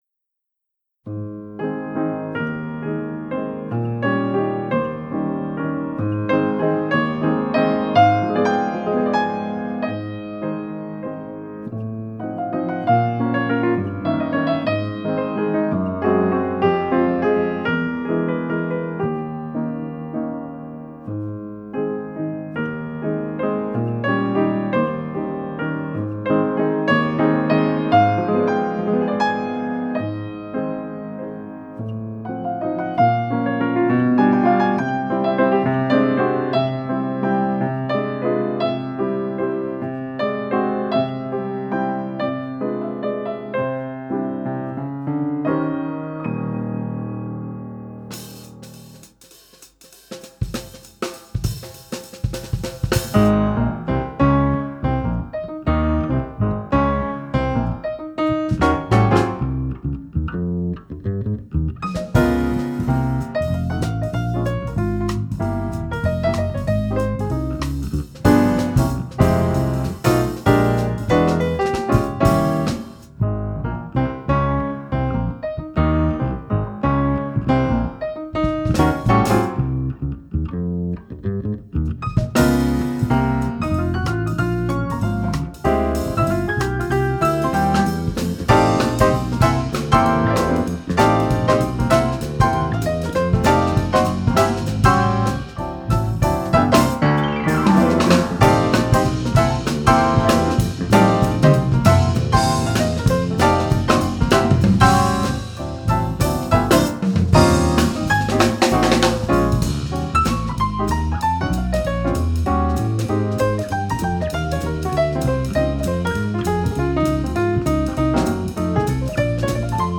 П.И.Чайковский - Декабрь (Времена года) - Трио Сергея Жилина